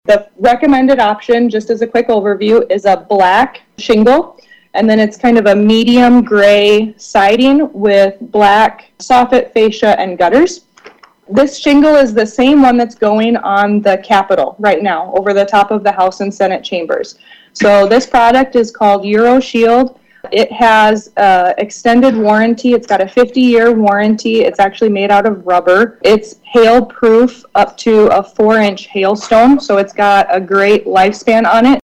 During a meeting of the Capital Complex Beautification and Restoration Commission last week (April 16, 2025)